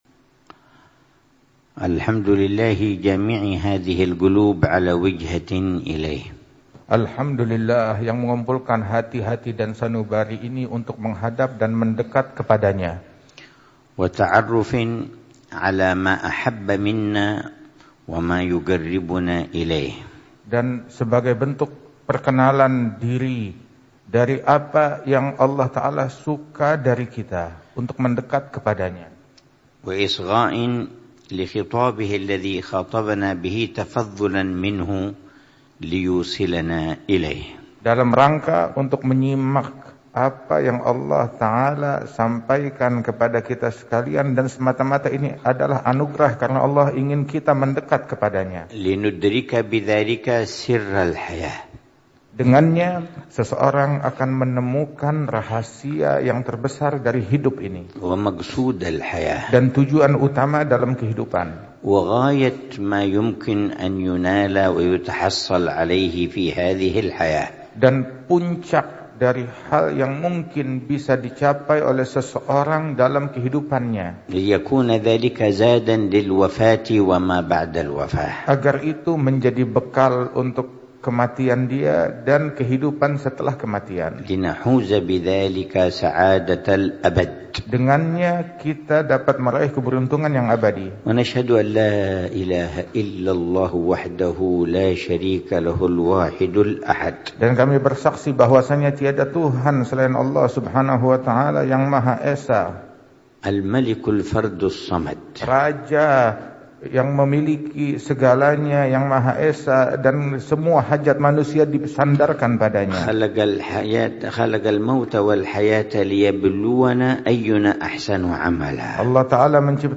محاضرة العلامة الحبيب عمر بن حفيظ في اللقاء مع أهل الفن والمؤثرين في الإعلام، ثم المداخلات والأسئلة، يوم السبت 26 ربيع الثاني 1447هـ بعنوان: